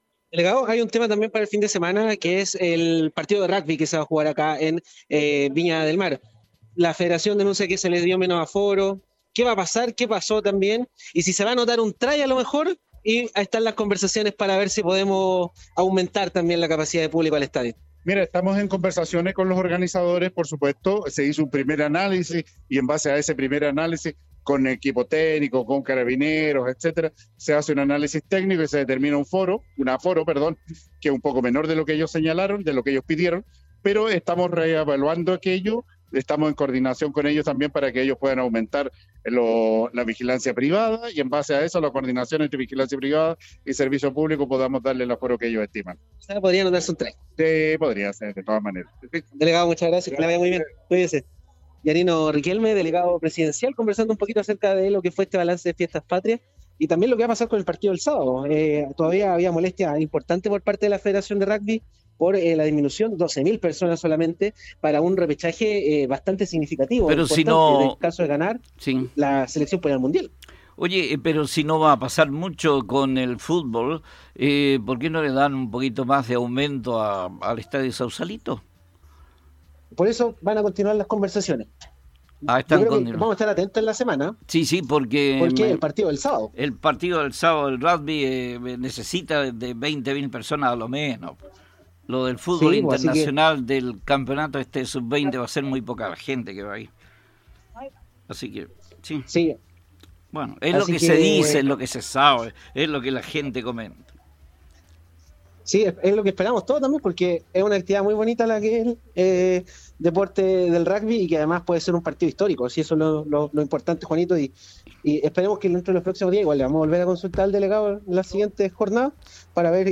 Esta mañana en conversación con Radio Festival, el delegado presidencial Yanino Riquelme afirmó que están en conversaciones para el aumento de público para este importante partido ante Samoa